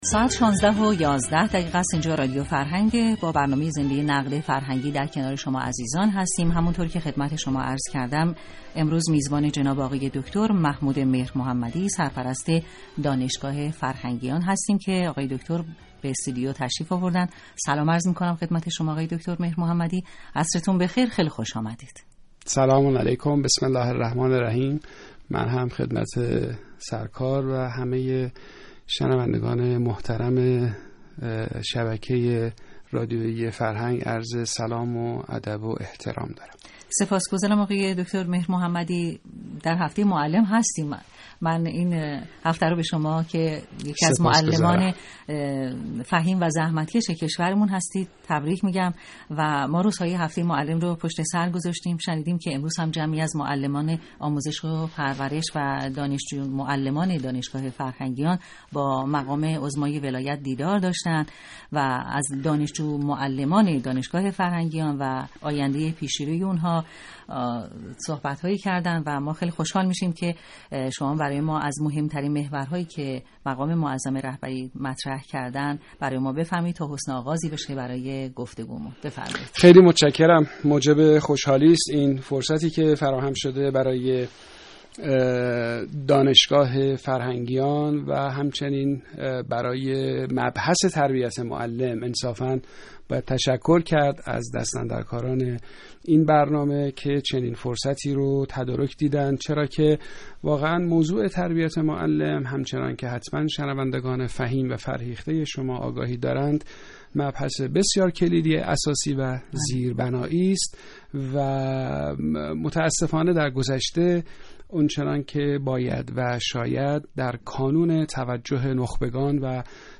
فایل صوتی مصاحبه